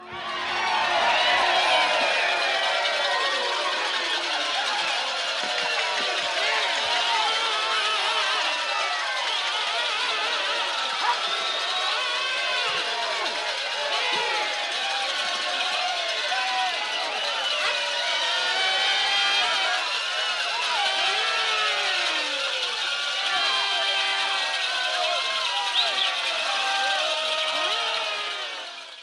14. Громкие, шумные party звуки